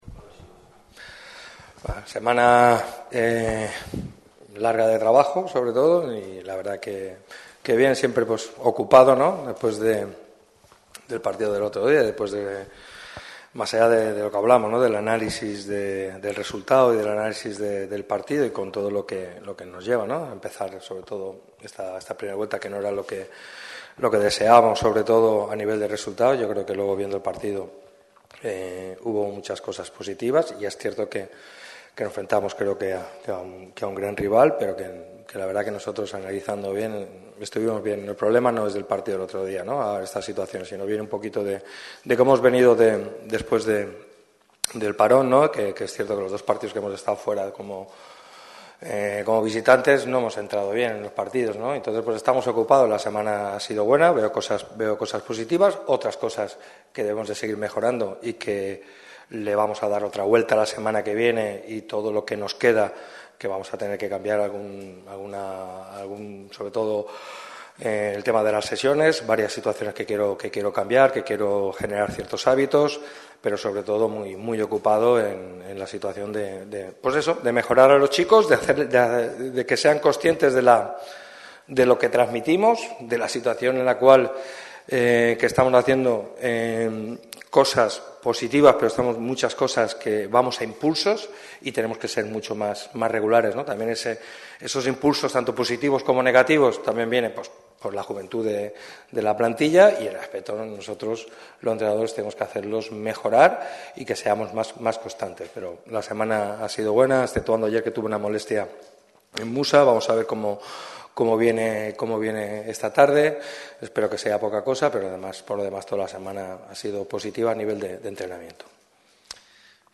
El técnico malaguista ha comparecido ante los medios en la sala de prensa de La Rosaleda con motivo de la previa del duelo que enfrentará a los boquerones ante el Recreativo Granada el sábado a las 16:00 horas. Pellicer repasa el estado de los lesionados, cómo afrontan este partido y habla sobre el futuro cercano del equipo.